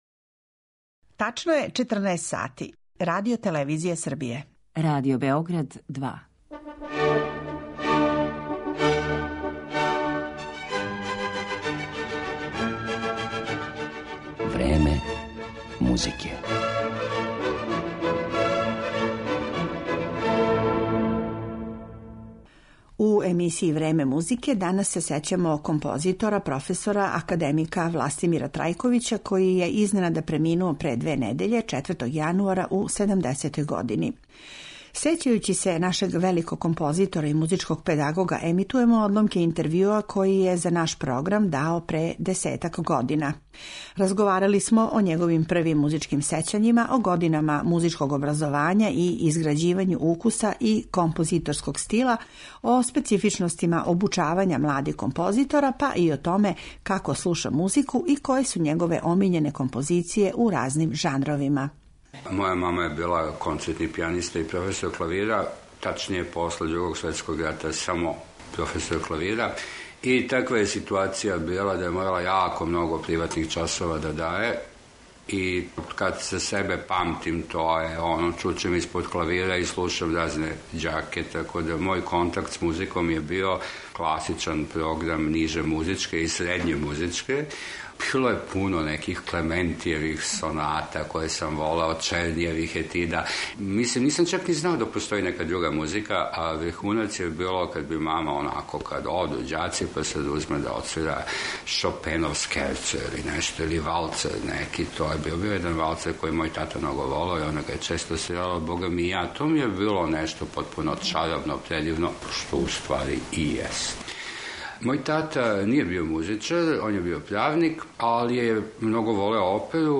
Емитоваћемо одломке интервјуа који је дао нашем програму пре дестак година, као и музичка дела која је одабрао као посебно важна за развој властитог стила и уметничког израза.